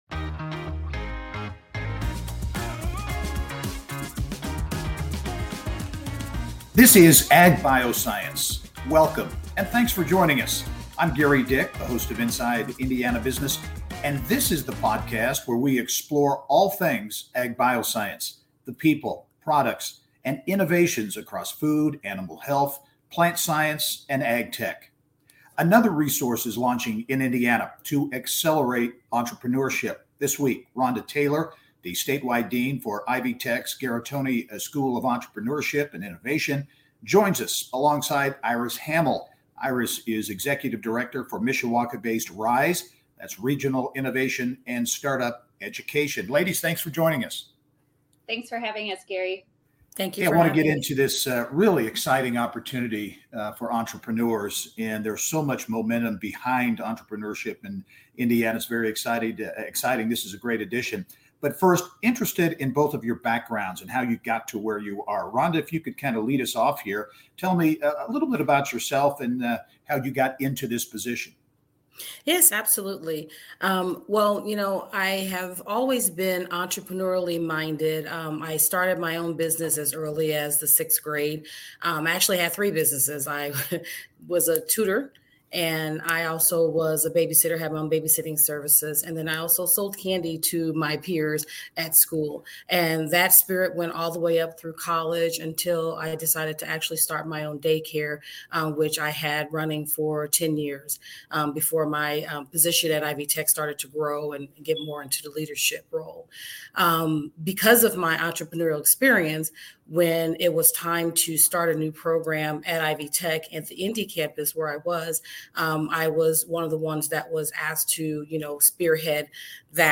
panel episode